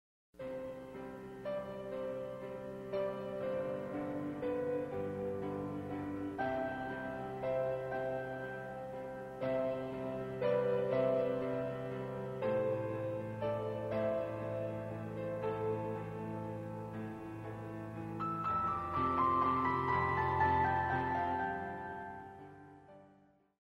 34 Piano Selections